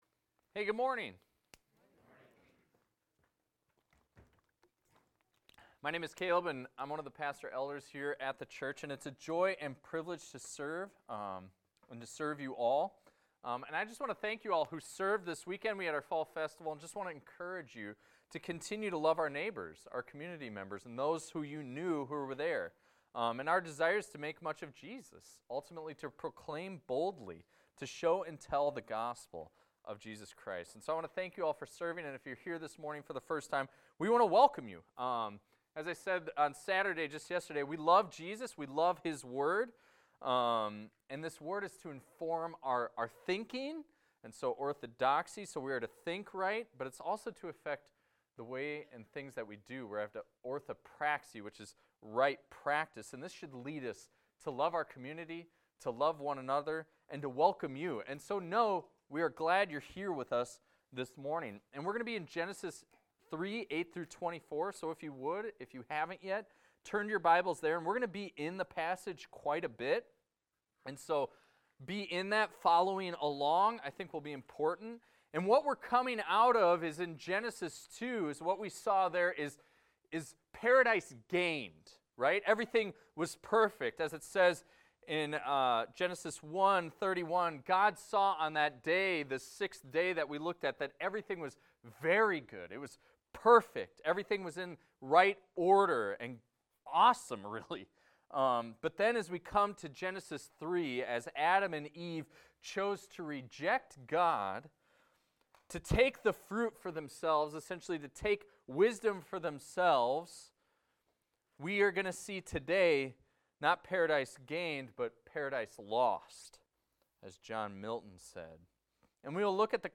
This is a recording of a sermon titled, "Shifting the Blame."